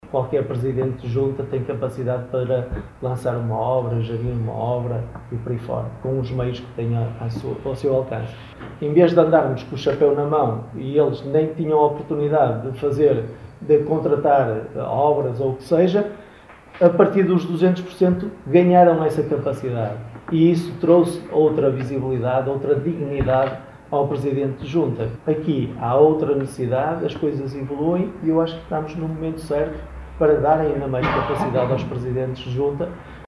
O PS de Barcelos apresentou, na tarde desta segunda-feira em conferência de imprensa, o compromisso eleitoral para os próximos 4 anos.